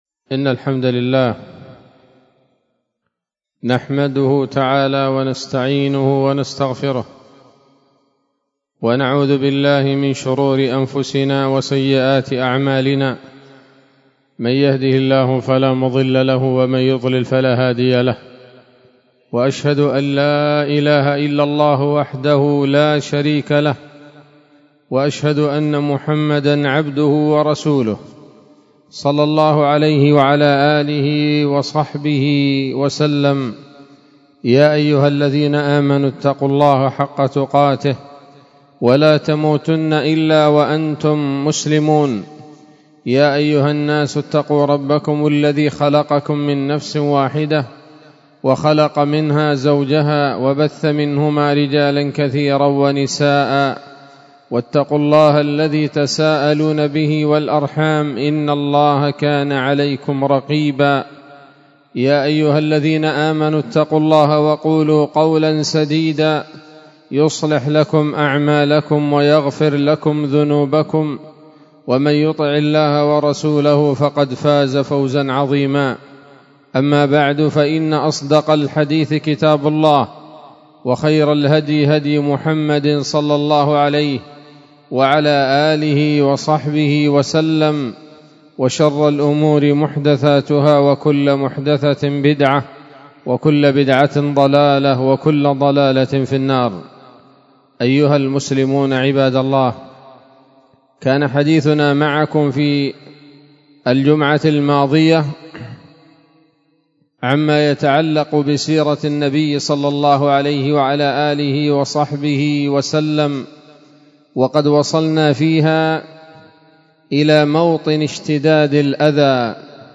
خطبة جمعة بعنوان: (( السيرة النبوية [7] )) 16 جمادى الآخرة 1445 هـ، دار الحديث السلفية بصلاح الدين